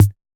Index of /musicradar/retro-drum-machine-samples/Drums Hits/Tape Path A
RDM_TapeA_MT40-Kick02.wav